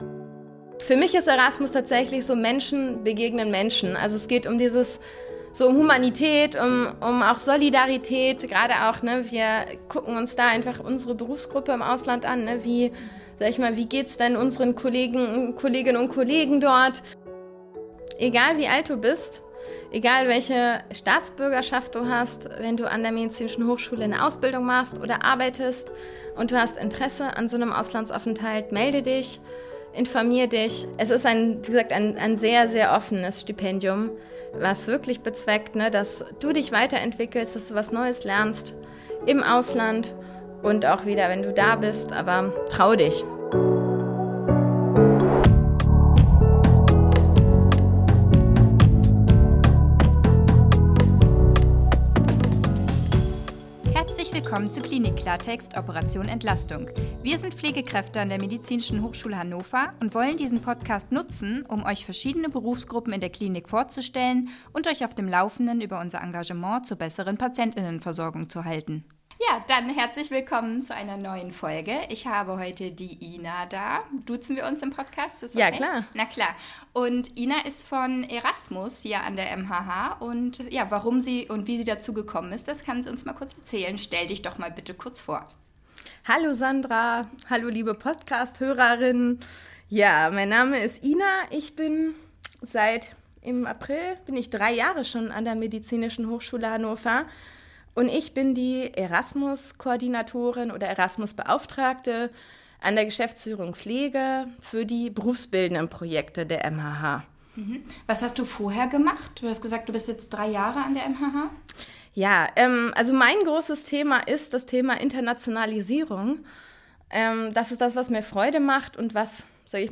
Zudem kommen mehrere Stimmen zu Wort, die sich derzeit im Erasmus-Aufenthalt an der MHH befinden oder die in einem anderen europäischen Land bereits eine Erfahrung im Rahmen des Programms gemacht haben.
Interview